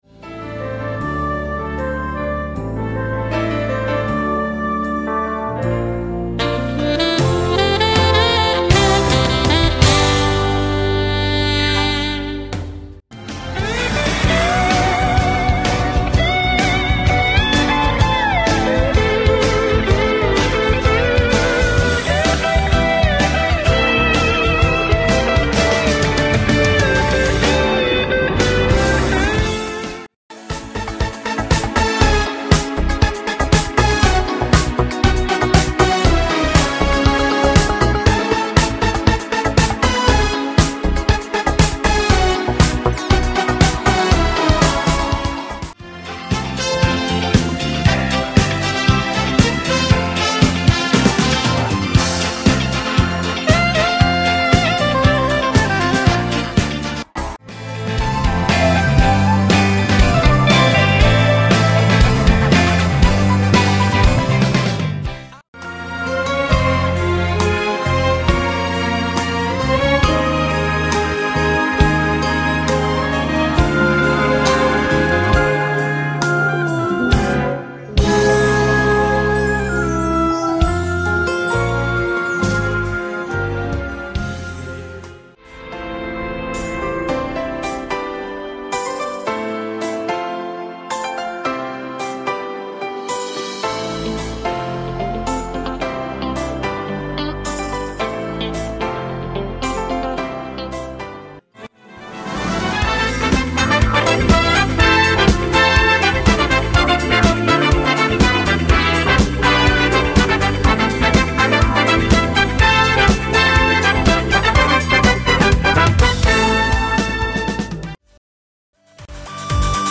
עם 10 שירים חסידי / אלטרנטיבי. חלקם קלים חלקם פחות, ישנים וחדשים...